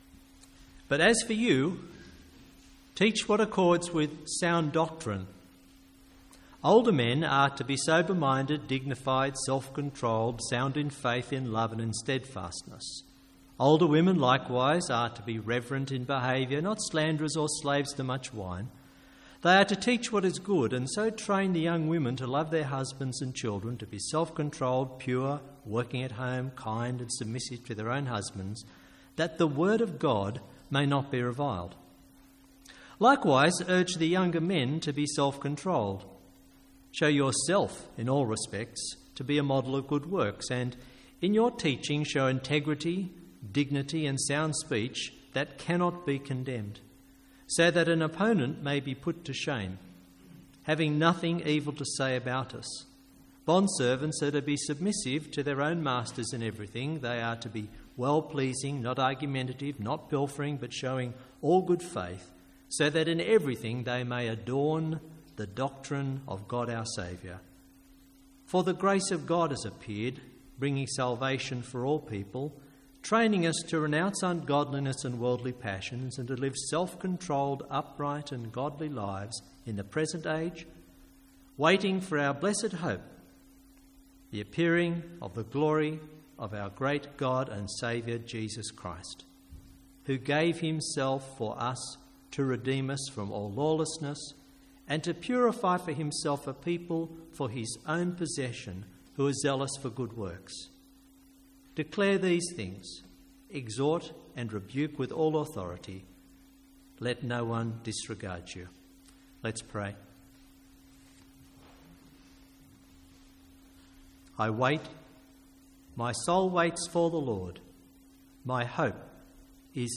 A one-off sermon at the Sunday evening service.